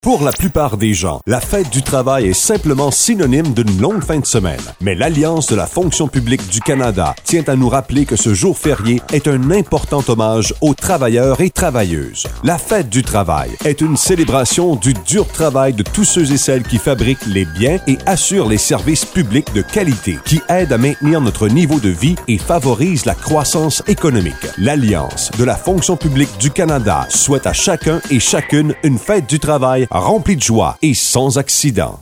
À partir du 31 août, l’AFPC-Atlantique diffusera des annonces à la radio pour commémorer cette journée importante dans les quatre provinces.